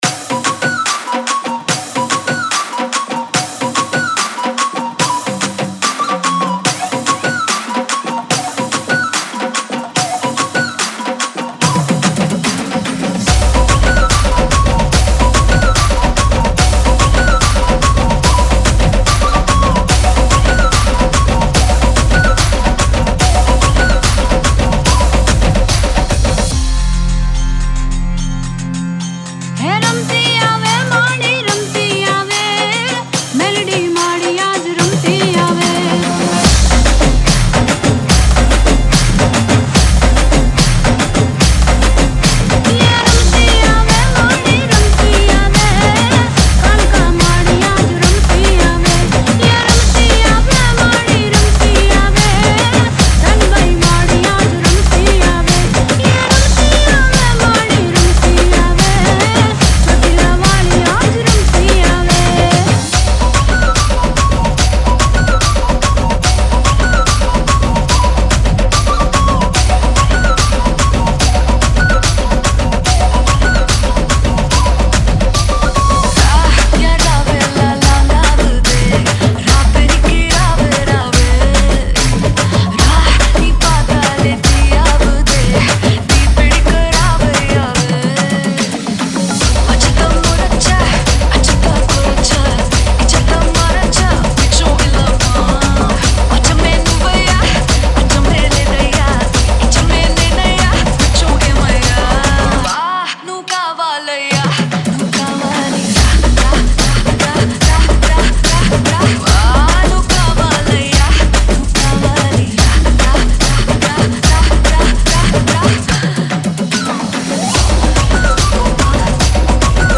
Navratri Dj Remix Song Play Pause Vol + Vol